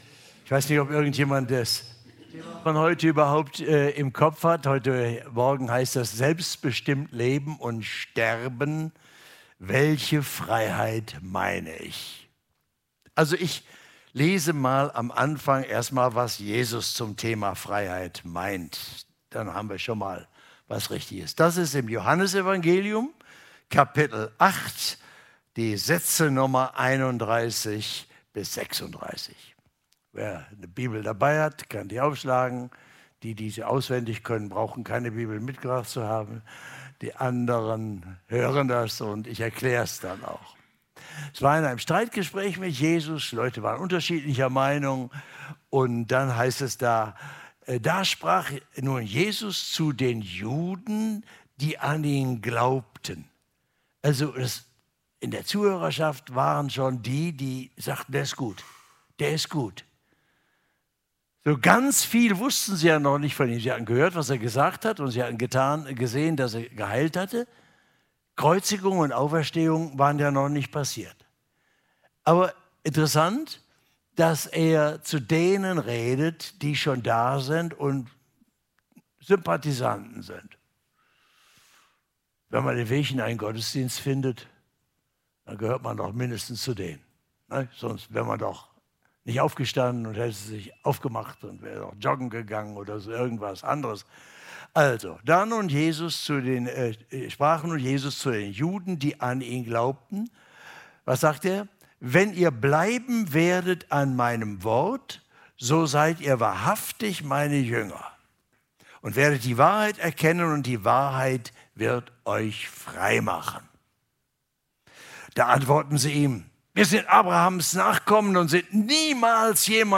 31-36 Gottesdienstart: Allianz-Gottesdienst Für uns bedeutet Freiheit ein Leben ohne Bindung